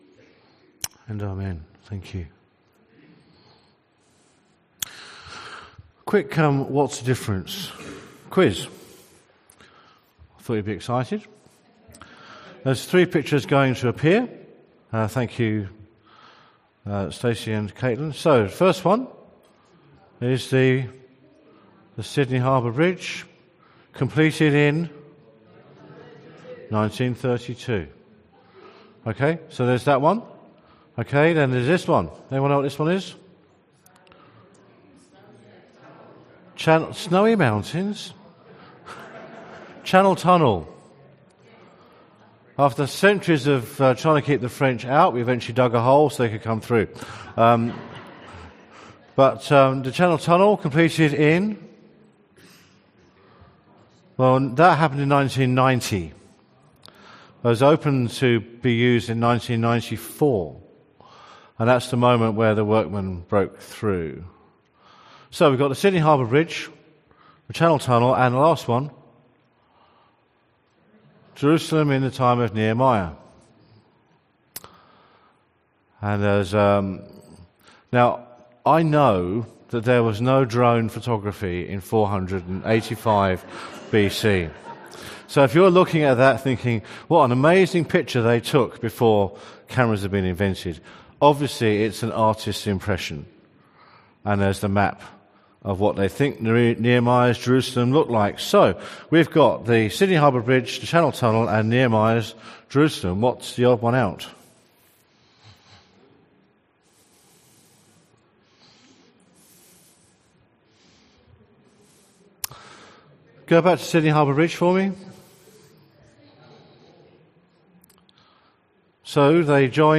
Sermon from the 10AM meeting at Newcastle Worship & Community Centre of The Salvation Army.